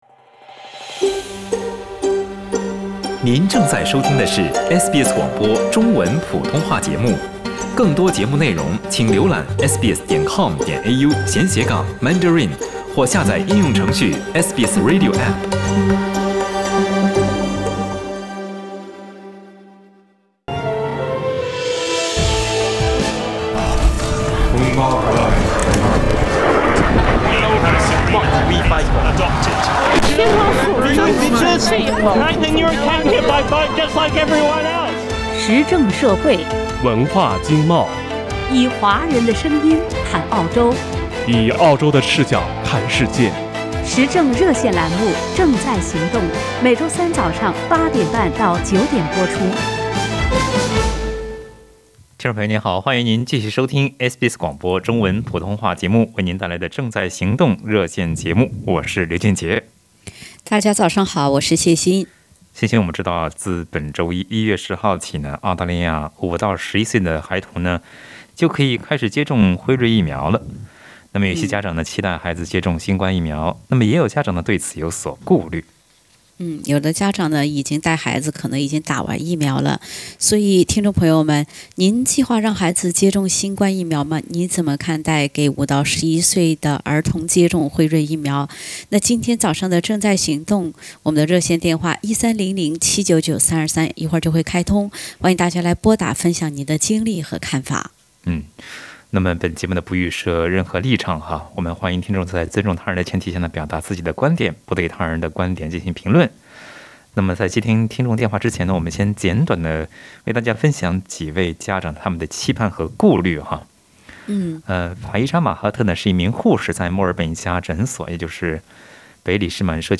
在本期《正在行动》热线节目中